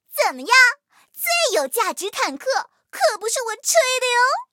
M4谢尔曼MVP语音.OGG